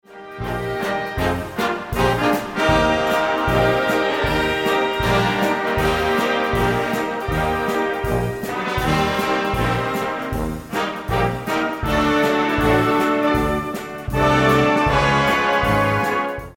Besetzung: Blasorchester
Mindestbesetzung: ab Big Band Besetzung.